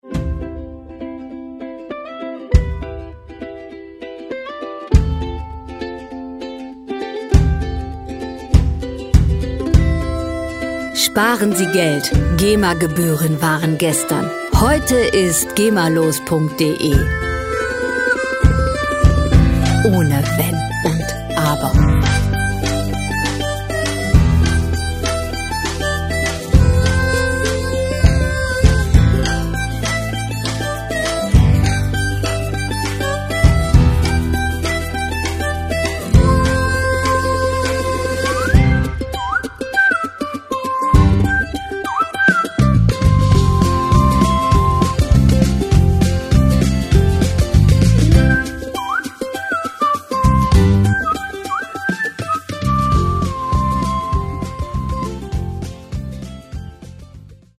Rockmusik - Naturfreunde
Musikstil: Acoustic Pop
Tempo: 100 bpm
Tonart: C-Dur
Charakter: gemütlich, zuversichtlich